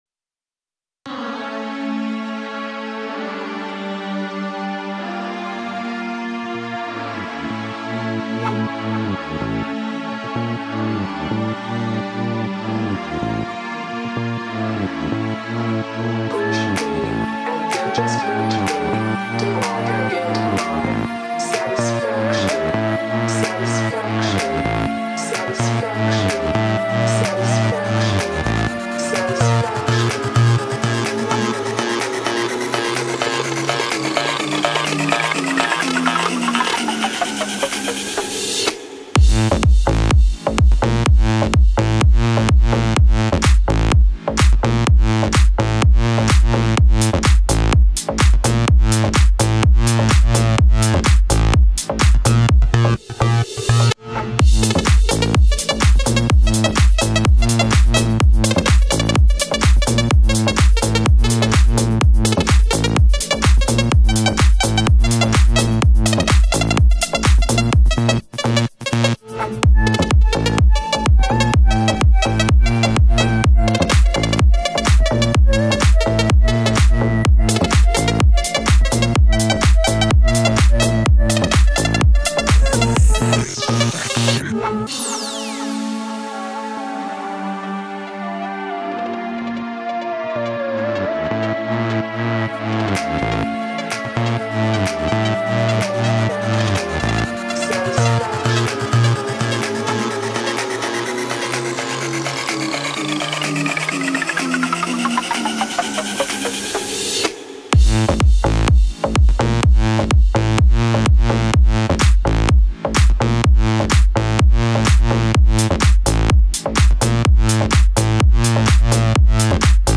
اهنگ بی کلام برای ورزش در خانه خارجی سیستمی